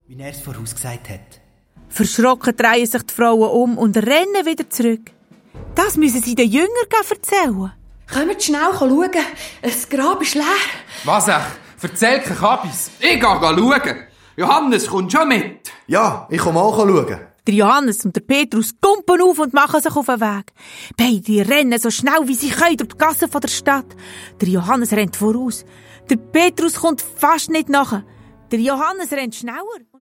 Zusätzlich enthalten: Mehrere Songs, das Hörspiel „D’Chätzli im Heizigschäller“ der lustigen Bärenkinder der Adonia-KidsParty.
Hörspiel-Album